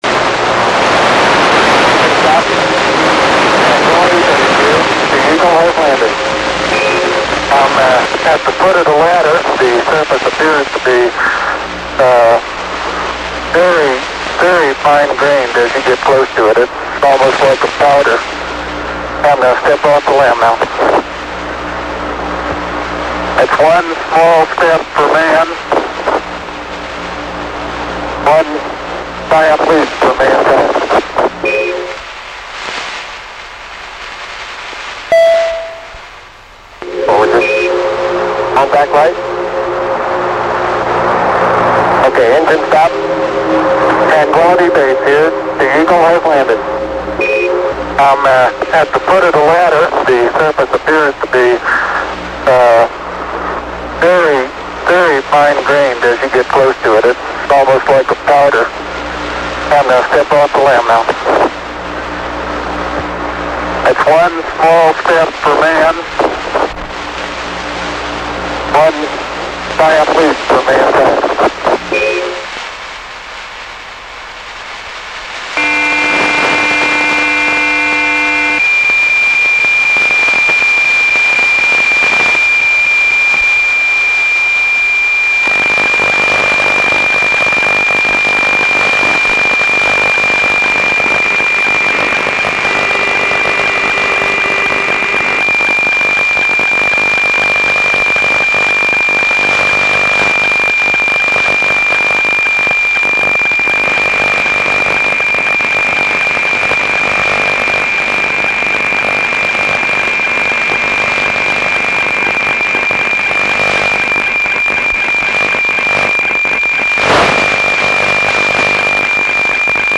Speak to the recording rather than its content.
His recording includes the recording of the first man on the moon as well as subsequent data transmitted by AO-21. AO21_Apollo 11 voice and data.mp3